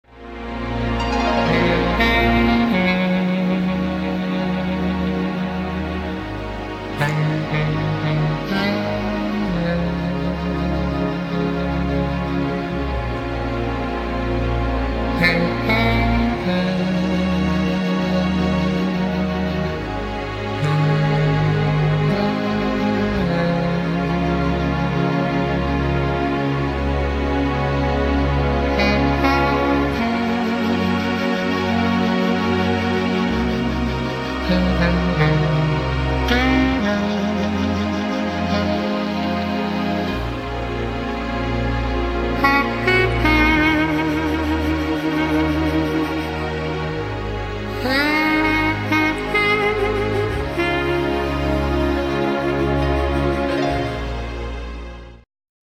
instrumental
version clarinette